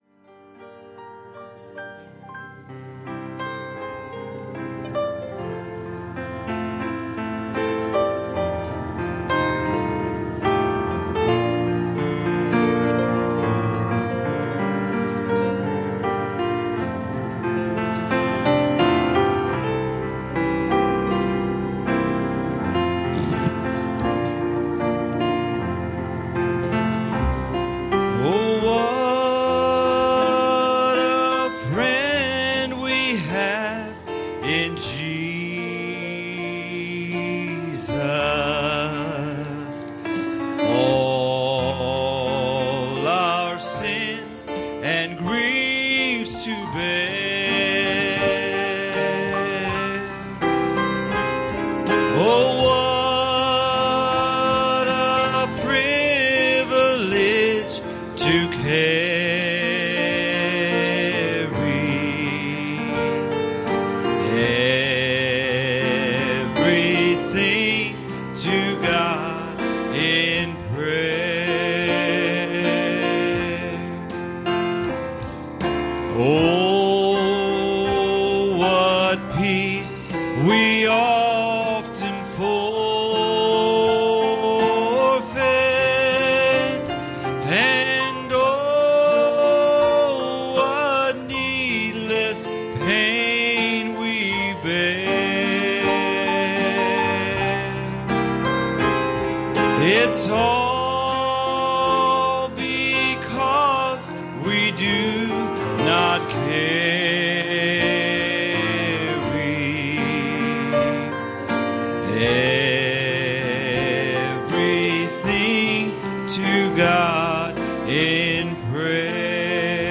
Series: Wednesday Evening Services